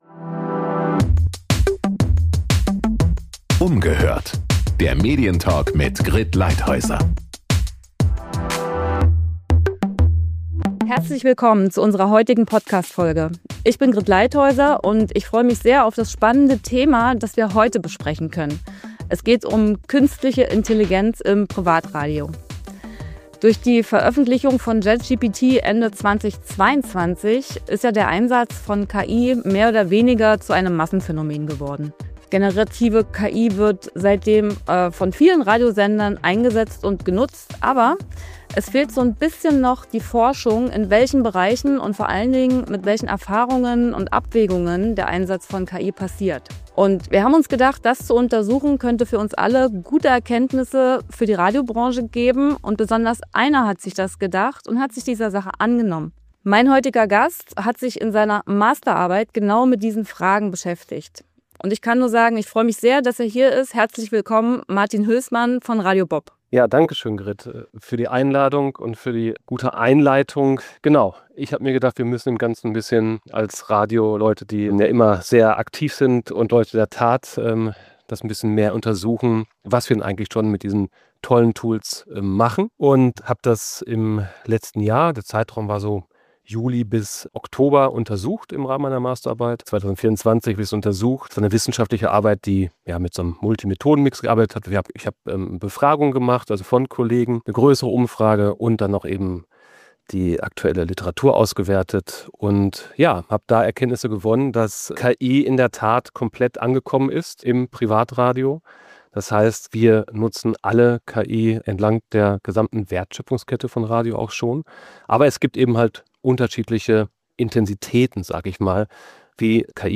Doch wie gelingt der Einsatz, ohne Authentizität und Glaubwürdigkeit zu verlieren? Ein Gespräch über Effizienz, neue Arbeitsweisen und die Berufsbilder in der Radiowelt von morgen.